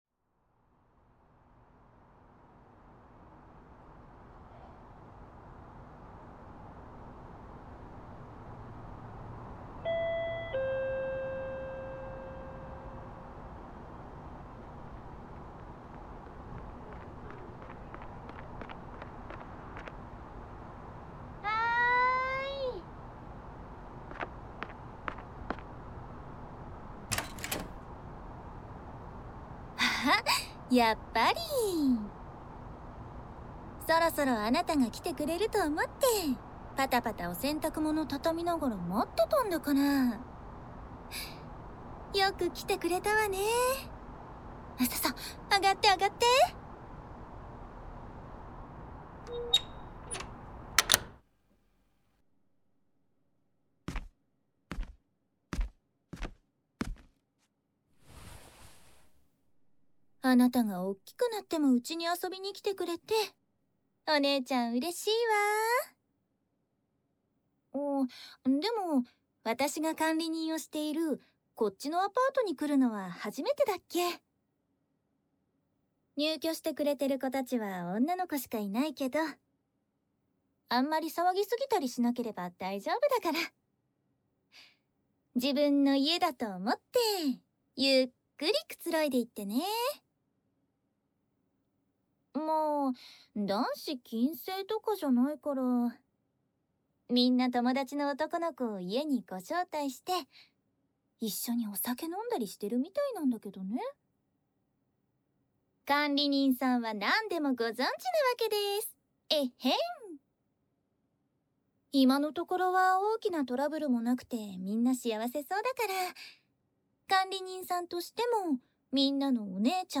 治愈 姐姐 系列作品 掏耳 环绕音 ASMR 低语